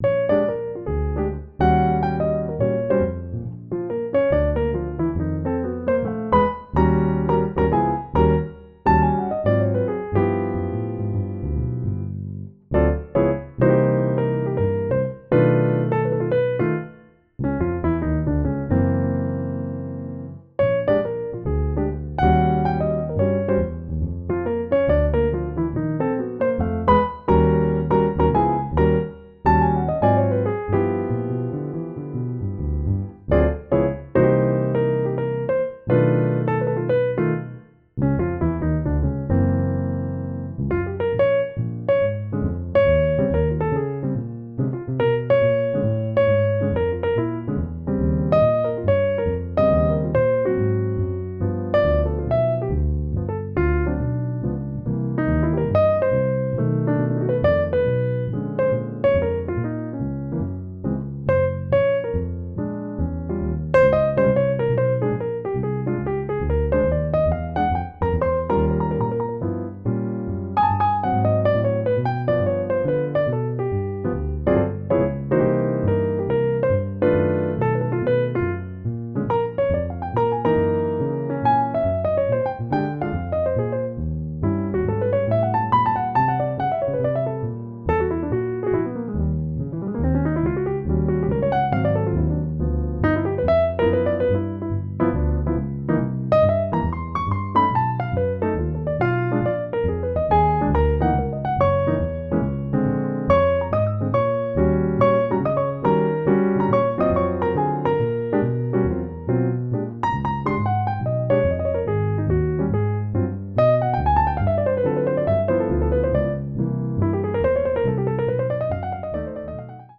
Physically modelled pianos and other instruments